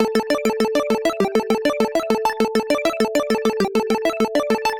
8比特声音
描述：Chiptune，或8位音乐是用于复古电脑（ZX Spectrum，Commodore 64......）的合成电子音乐，不要犹豫，用这些美丽的声音为你的音乐添加一丝色彩！
Tag: 100 bpm 8Bit Chiptune Loops Synth Loops 827.27 KB wav Key : A FL Studio